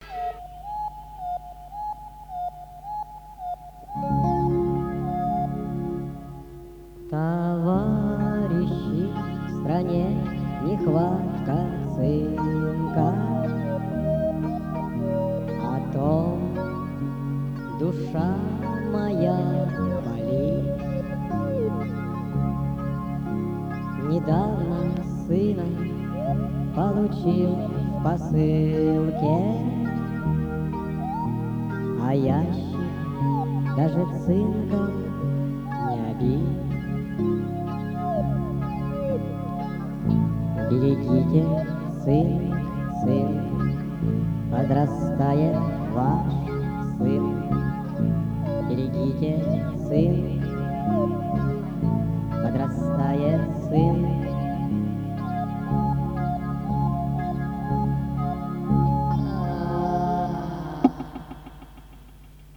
Это в гейскую тему Ли...Пестня печальная...